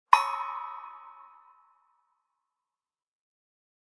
Descarga de Sonidos mp3 Gratis: clang 8.